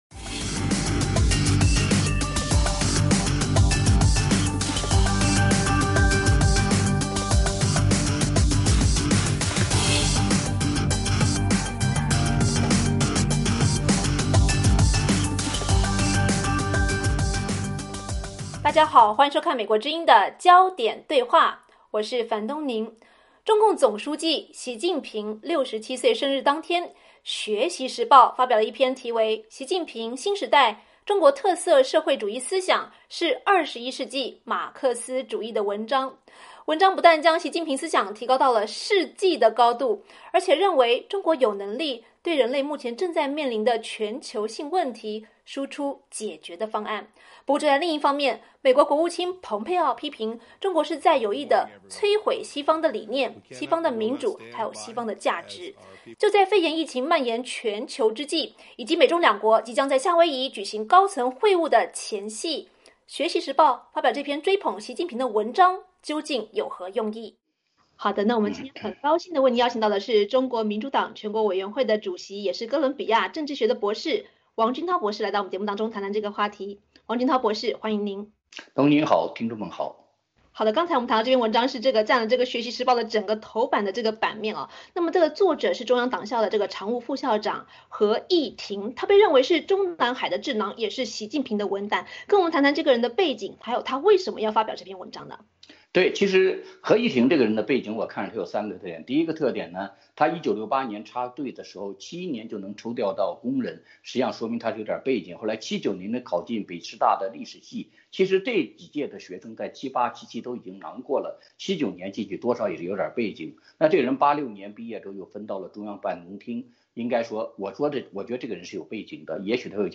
就在肺炎疫情蔓延全球之际以及美中夏威夷高层会晤的前夕，《学习时报》发表这篇追捧习近平的文章有何用意？我们今天很高兴为您请到，中国民主党全国委员会主席王军涛博士来谈谈这个话题，王军涛博士欢迎您。